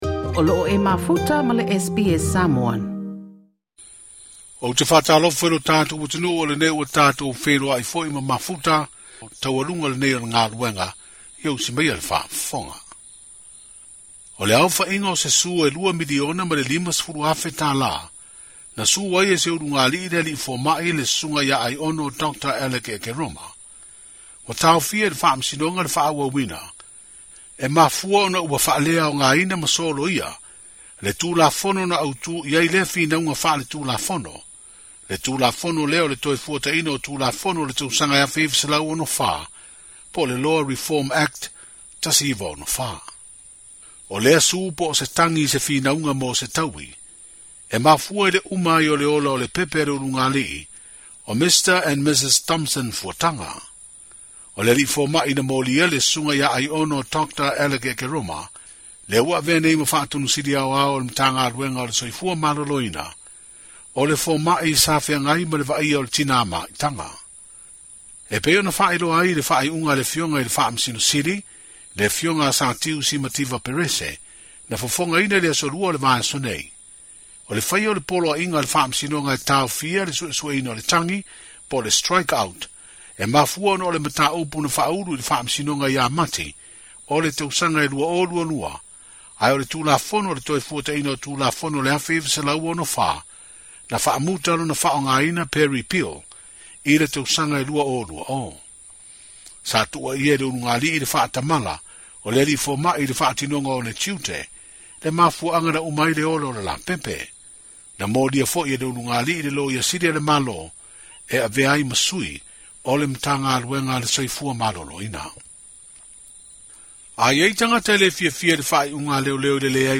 Na faamanino e le minisita o faamasinoga ma leoleo i Samoa, Faualo Harry Schuster, le tulaga o le tulafono pe a le taliaina e se tagata se faai'uga a leoleo i se mata'upu. E mafai ona faaulu sana tagi e faasaga i le matagaluega o leoleo, pe faaulu sana lava 'private prosecution' e iloiloina ai e le faamasinoga sana mata'upu.